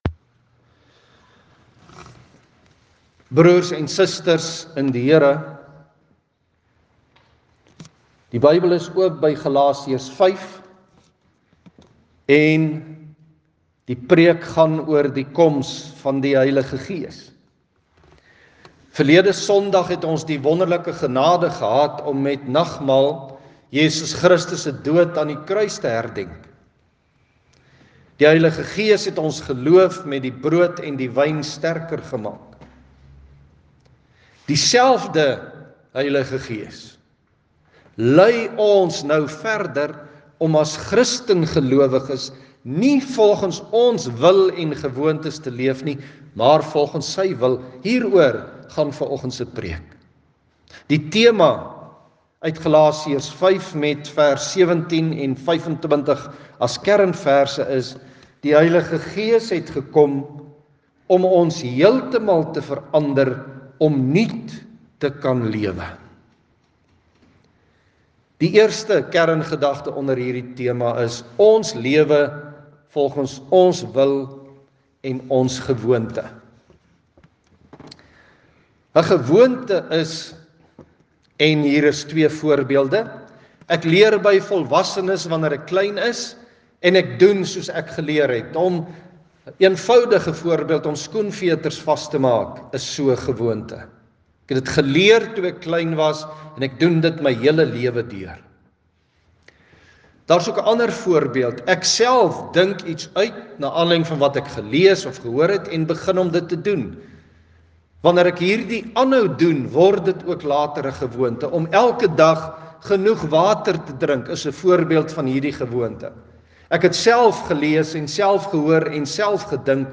Dieselfde Heilige Gees lei ons om as Christengelowiges nie volgens ons wil en gewoontes te leef nie, maar volgens sy wil. Hieroor gaan vanoggend se preek.